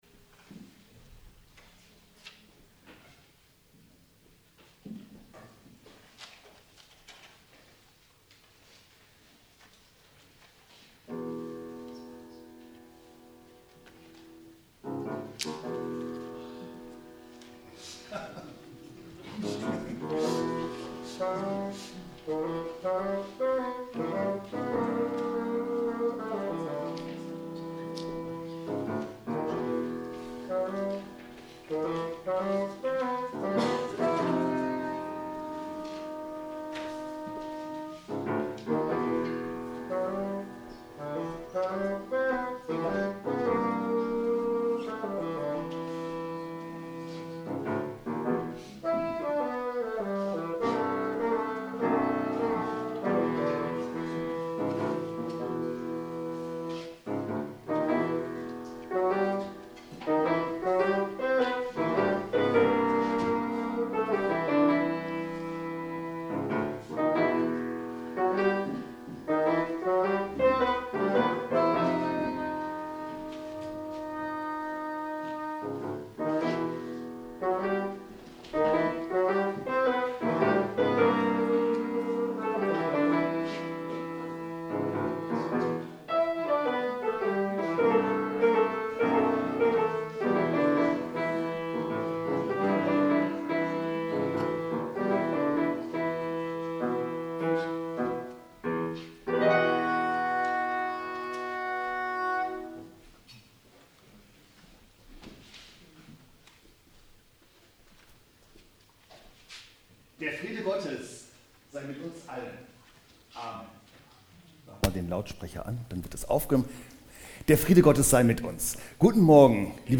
Gottesdienst - 15.02.2026 ~ Peter und Paul Gottesdienst-Podcast Podcast